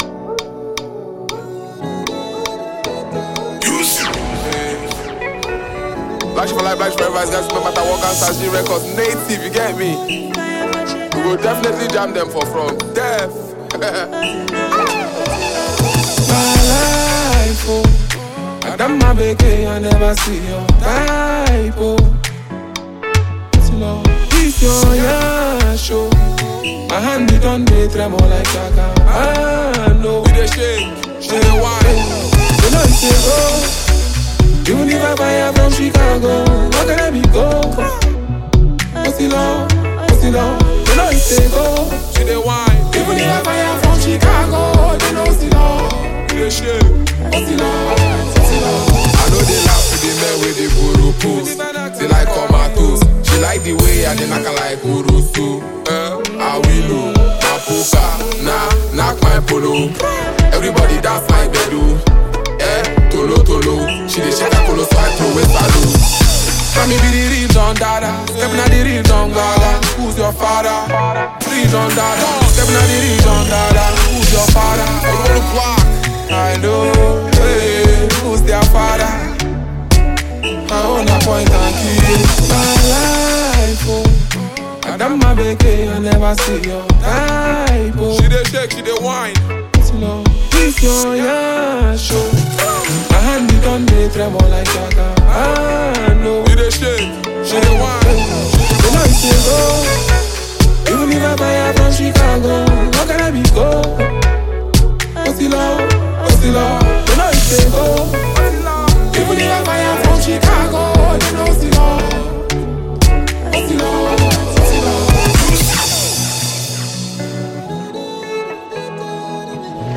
Phenomenon talented Nigerian rap artist and performer